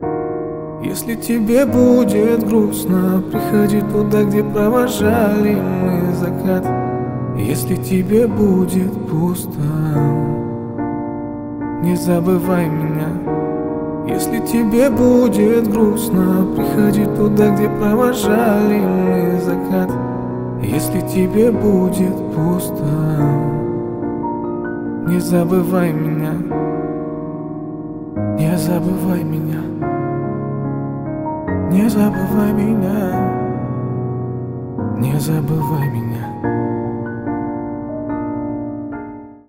Поп Музыка
спокойные # кавер # грустные